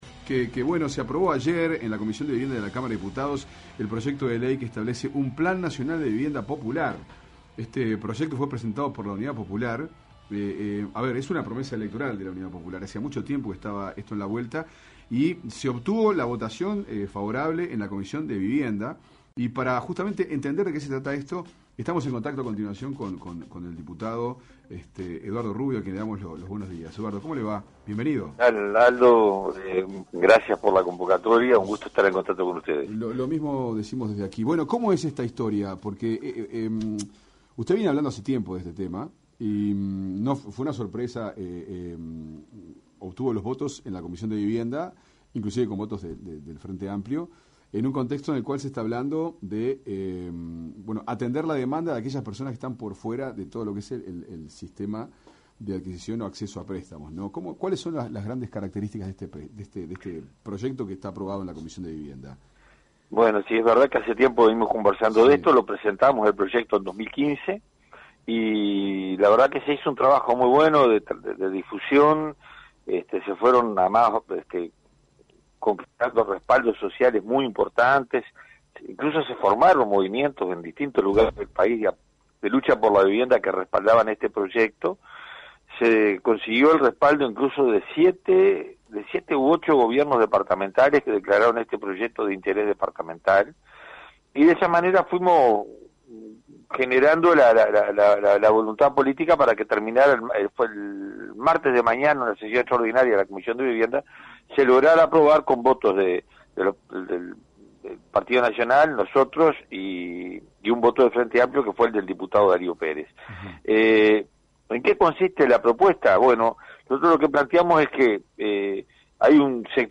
Se aprobó en la Comisión de Vivienda de la Cámara de Diputados el Plan Nacional de Vivienda Popular, propuesta de la Unión Popular. Fuentes Confiables dialogó con el Diputado Eduardo Rubio sobre este tema.